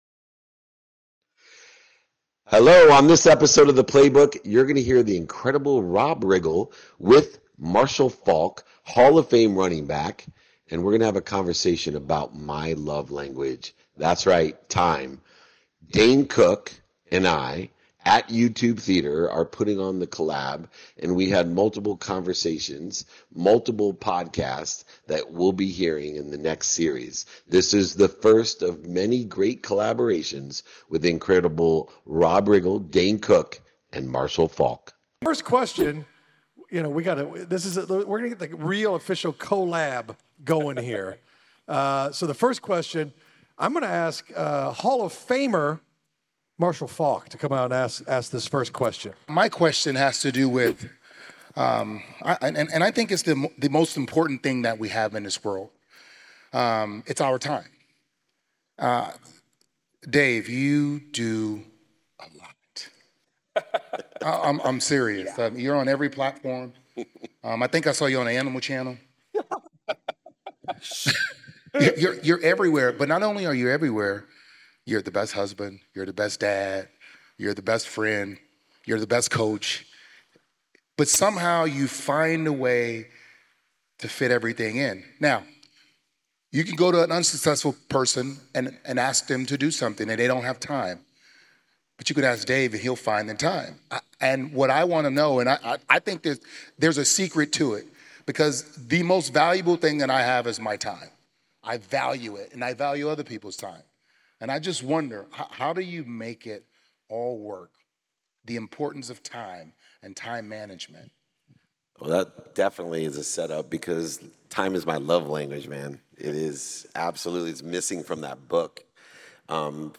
In today’s episode, I’m joined by NFL Hall of Famer Marshall Faulk, actor Rob Riggle, and comedian Dane Cook. Marshall kicks off our discussion with a powerful question about the importance of time management.